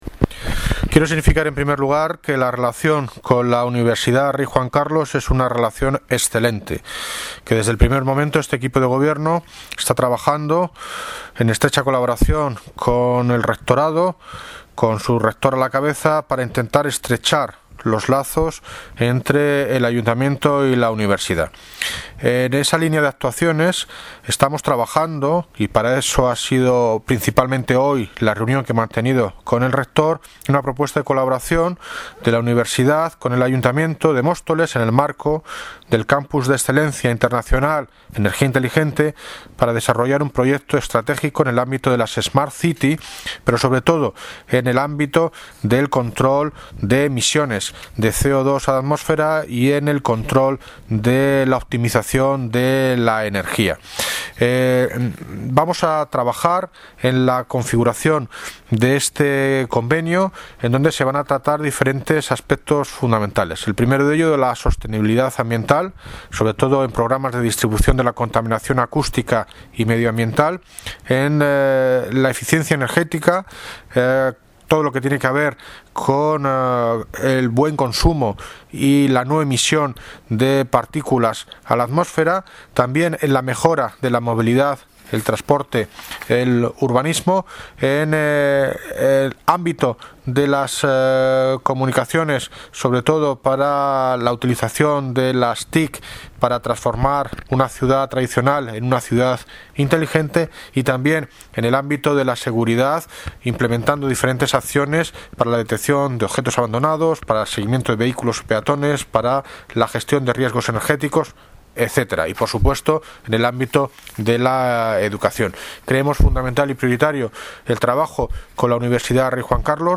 Audio - David Lucas (Alcalde de Móstoles) sobre proyecto URJC sostenibilidad energética